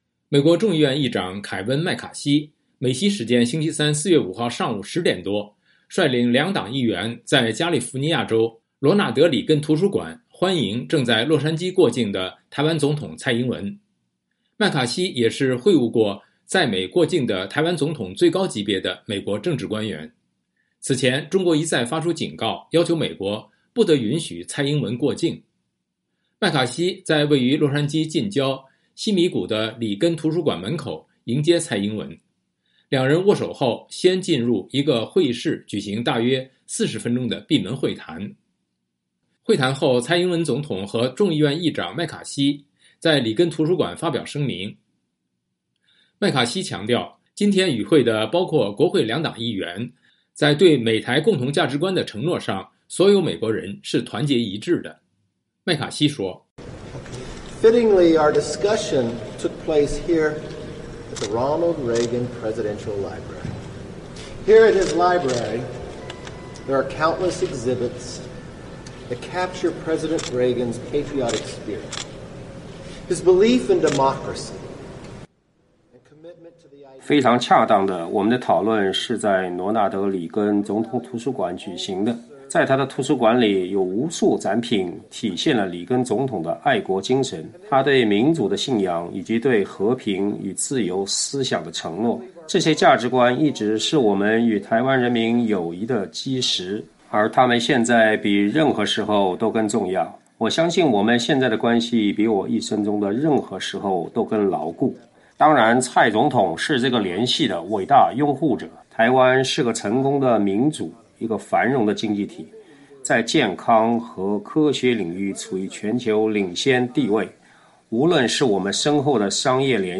新闻及采访音频